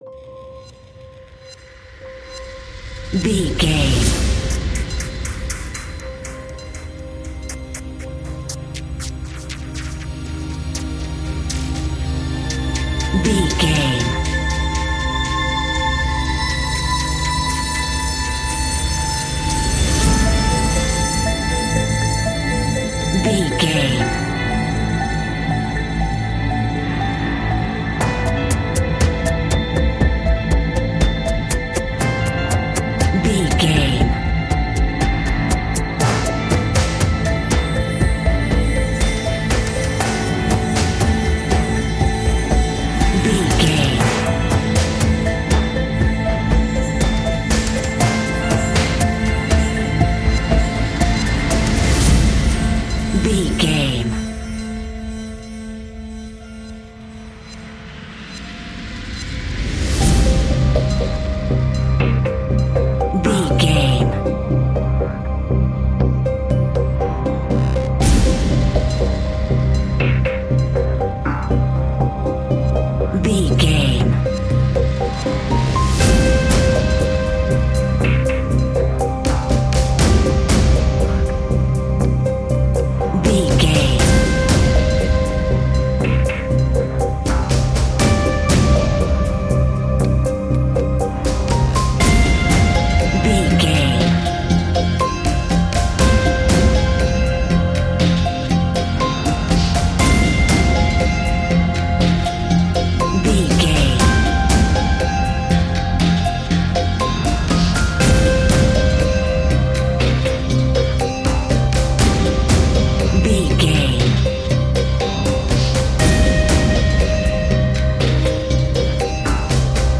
Ionian/Major
industrial
dark ambient
EBM
drone
experimental
synths
Krautrock
instrumentals